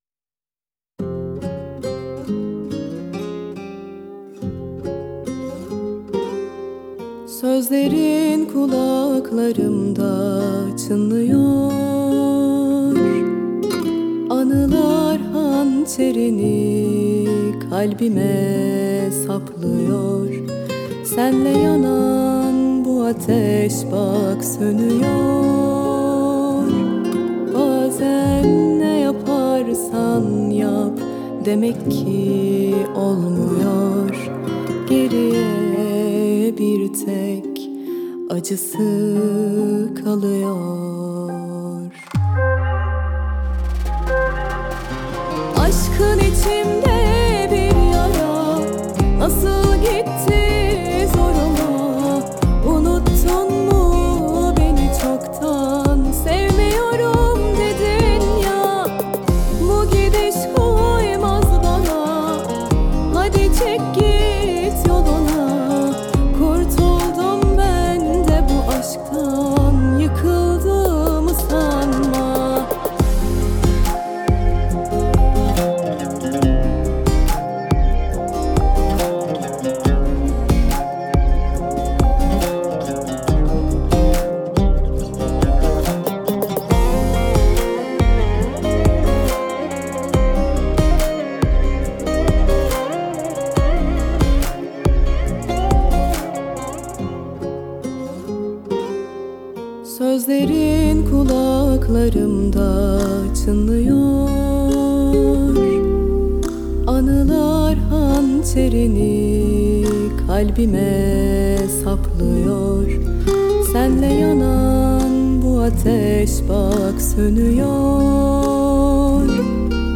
آهنگ ترکیه ای